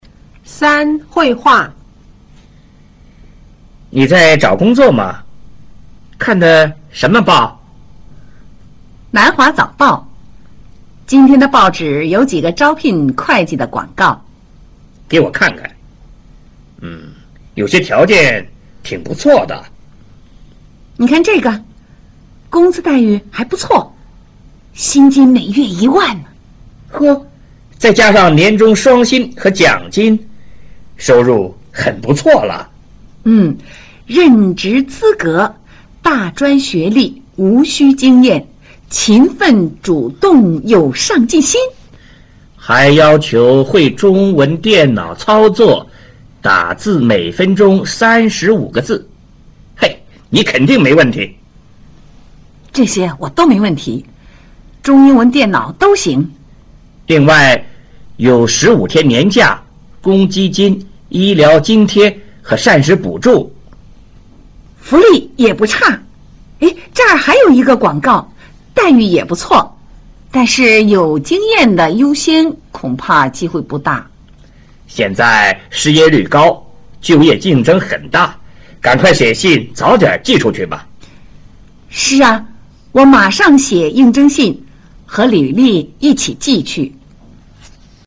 三、會話